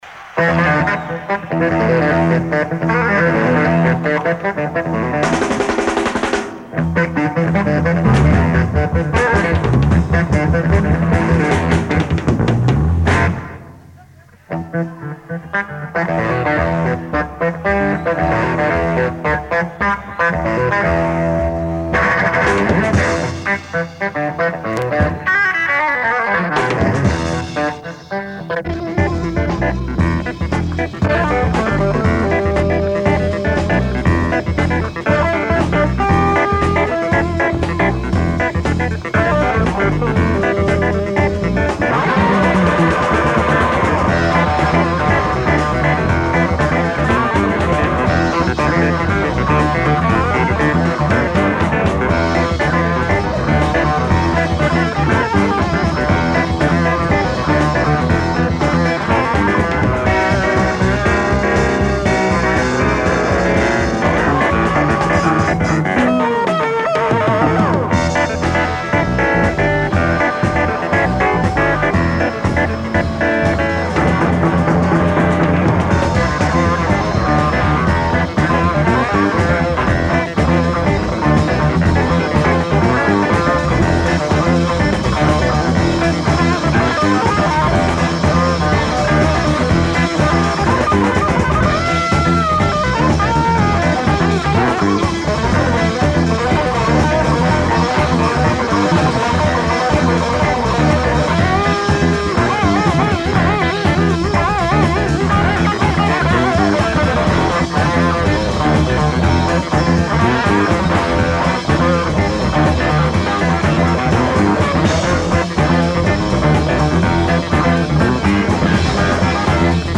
TYPE: Soundboard
SOUND QUALITY: TTTT½
una spiritata
a spirited